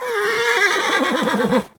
horse
taunt1.ogg